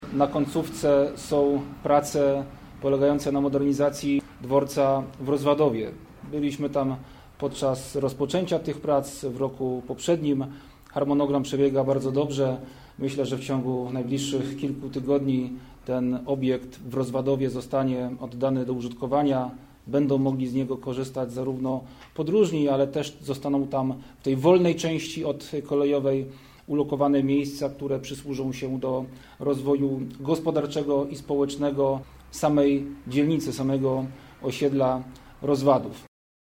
Kilka tygodni – tyle w ocenie wiceministra infrastruktury Rafała Webera potrwa jeszcze modernizacja Dworca Kolejowego w Stalowej Woli-Rozwadowie. Mówił o tym przy okazji konferencji dotyczącej dworca w Nisku